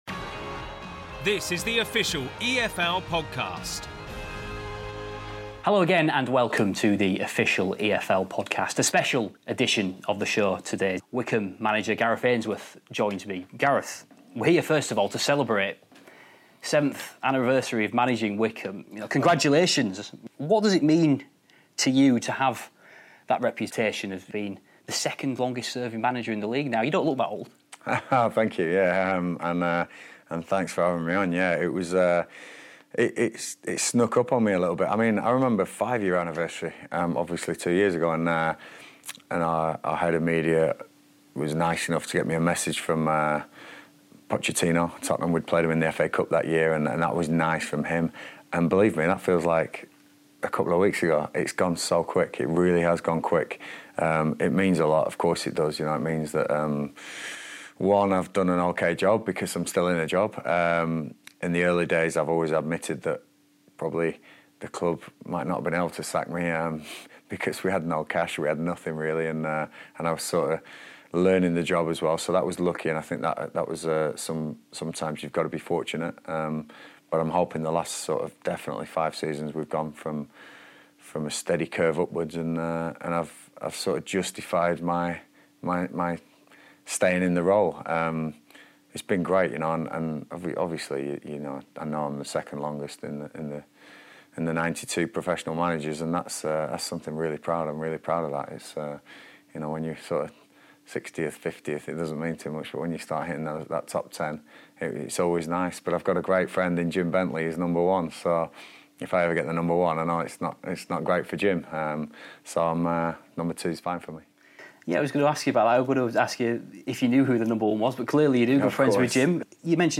In an in-depth interview